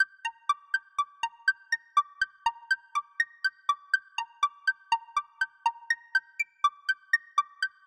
描述：电子电气和谁知道什么
标签： 122 bpm Electronic Loops Drum Loops 1.32 MB wav Key : Unknown
声道立体声